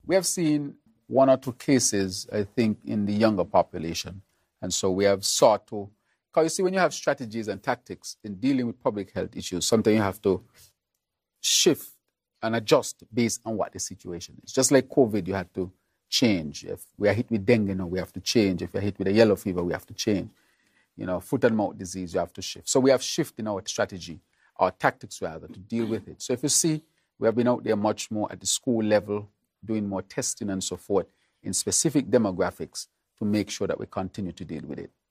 That was a question posed to Prime Minister, and Federal Minister of Health, Dr. Terrance Drew, on April 2nd, 2025, during his “Roundtable” Discussion.